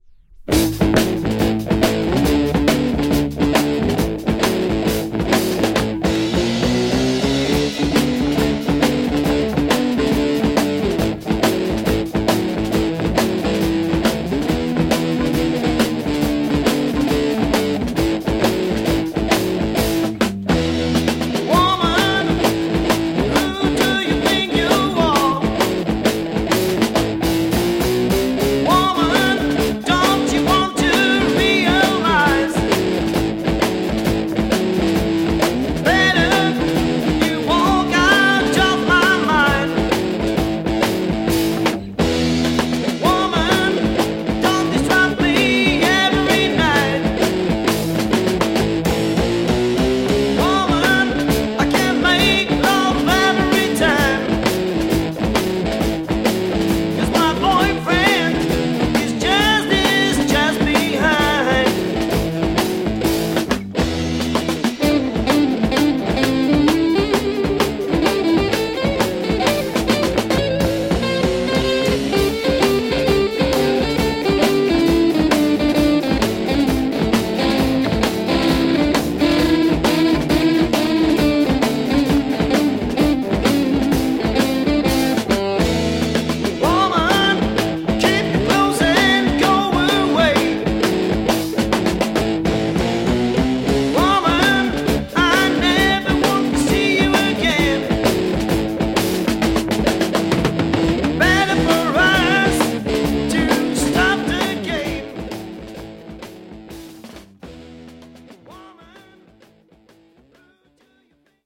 Underground Euro progressive rock single!